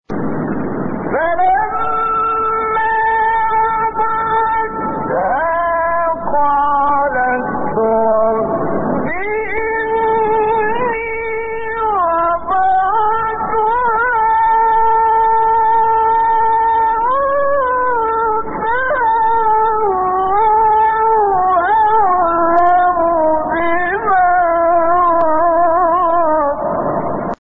گروه شبکه اجتماعی: فرازهای صوتی از سوره آل‌عمران با صوت کامل یوسف البهتیمی که در مقام‌های مختلف اجرا شده است، می‌شنوید.
مقام عجم